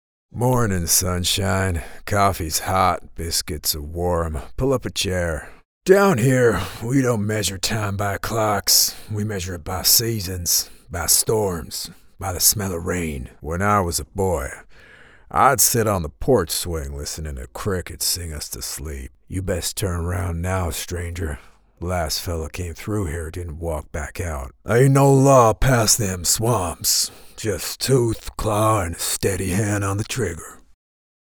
Southern US Accent Showreel
Male
Confident
Cool
Friendly